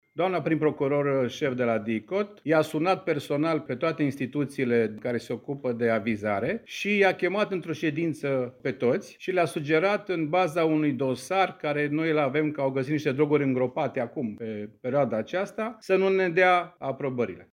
Într-o conferință de presă organizată cu ocazia deschiderii oficiale a sezonului estival de pe litoral